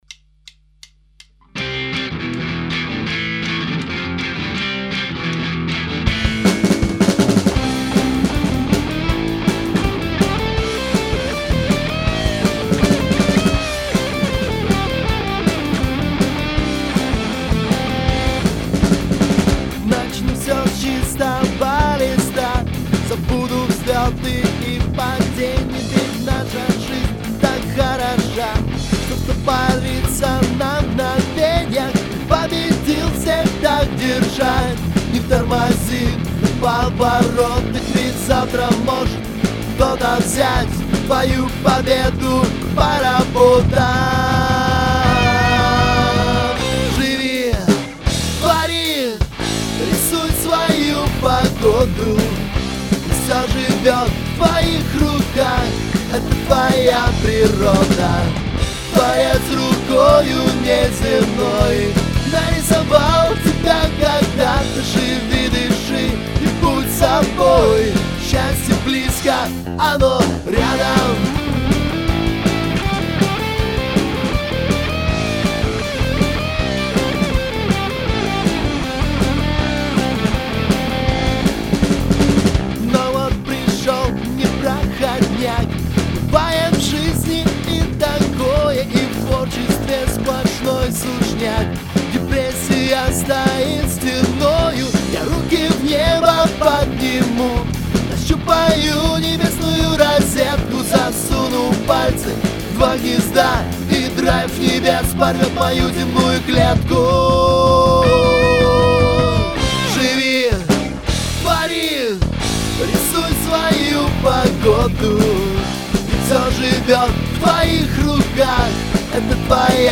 пишет барабаны:))) темп 160 поэтому все серьезно
Получилась запись, конечно мы ее еще доработаем, но самый первый вариант может даже сыроватый, но задорный и позитивный вы можете скачать здесь:)
Эт я :) пишем вокал:) пришлось петь за нескольких парней:)))